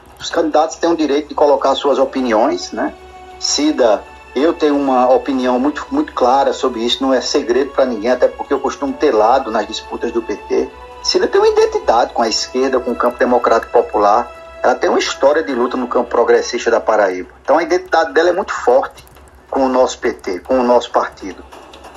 Os comentários do dirigente foram registrados durante o programa Arapuan Verdade, da Rádio Arapuan FM.